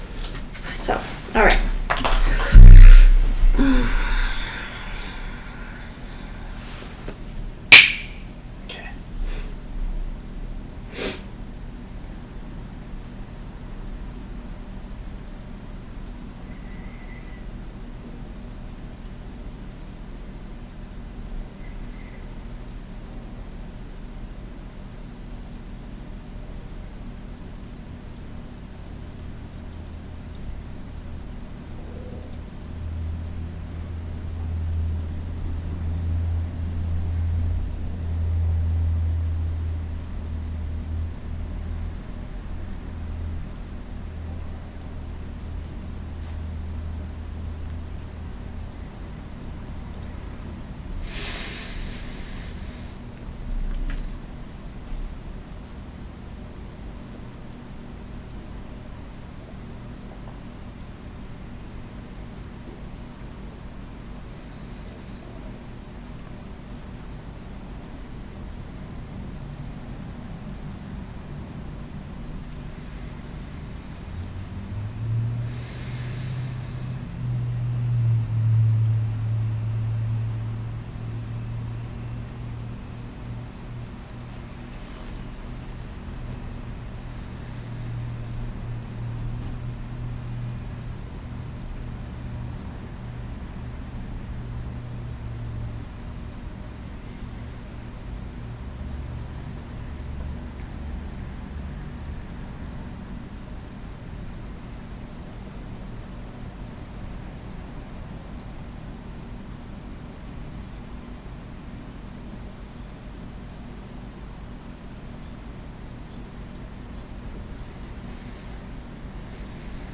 In this session from the Other Selves Working Group’s first channeling intensive in Colorado Springs, Q’uo discusses humility, starting with comparing our condition to that of the second density sapling vulnerably striving towards the light without self-consciousness. Q’uo then ties in false humility, arrogance, acceptance of self, protection, specialization, and authority to explore the experiences that we encounter as we hone the personality shells that third density provides us.